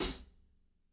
-18  CHH B-R.wav